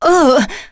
Seria-Vox_Damage_01.wav